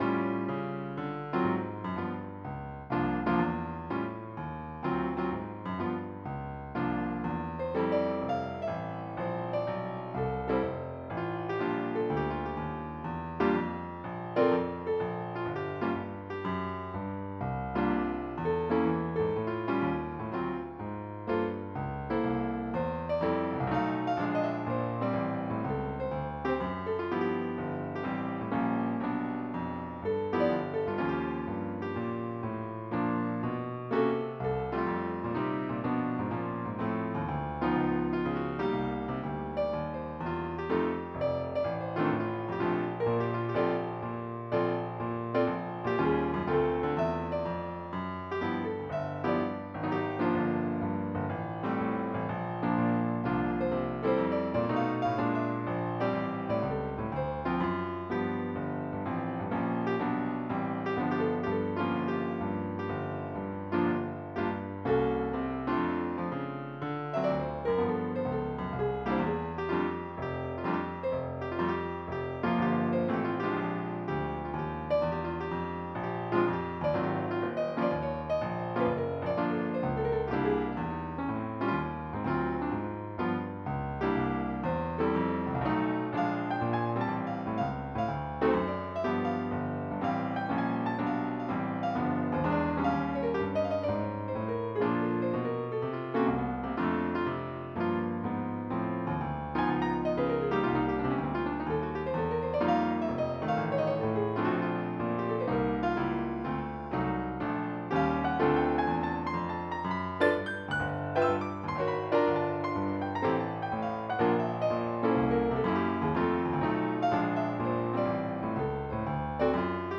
MIDI Music File
jazz62.mp3